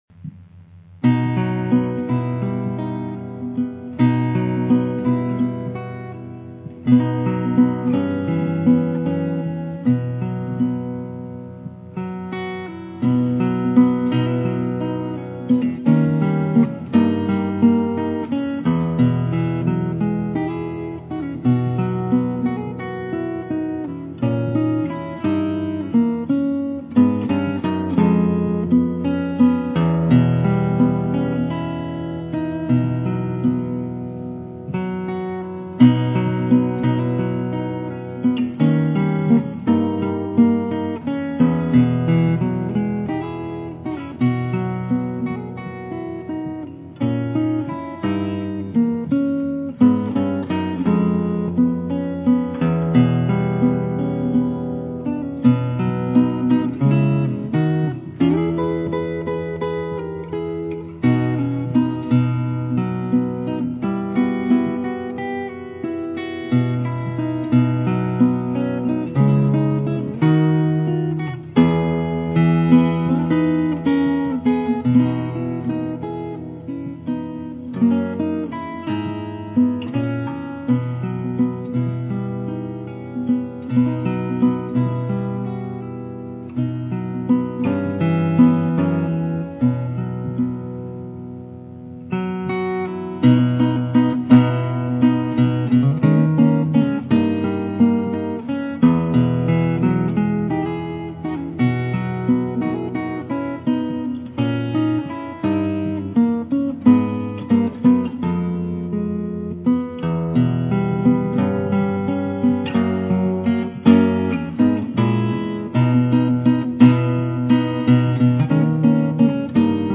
Baby Taylor Mahogany